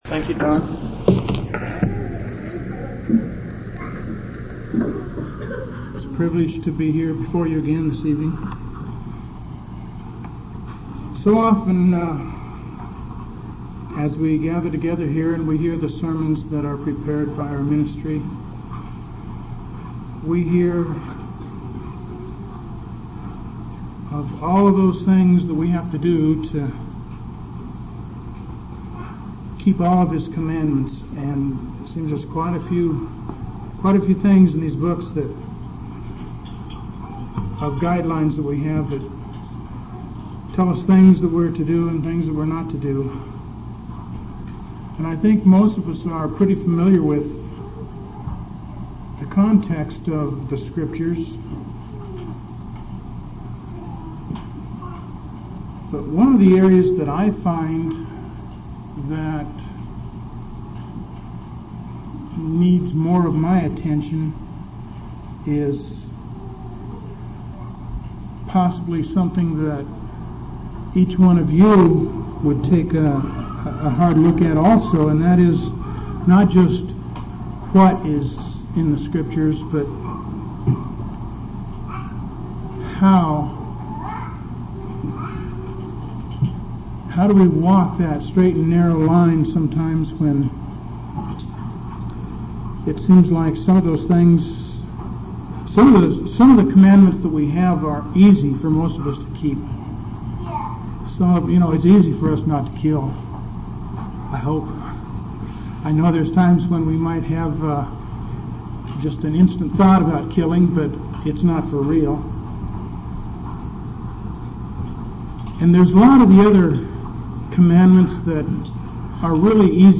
5/27/1984 Location: Phoenix Local Event
audio-sermons